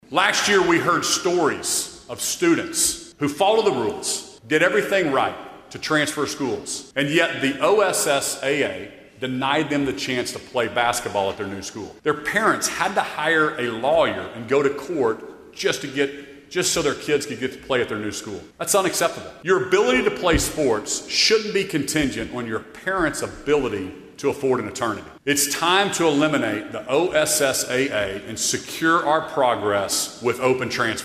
During Oklahoma Governor Kevin Stitt's final state of the state address on Monday, one of many wide-ranging topics he addressed was the ever-evolving world of high school athletics.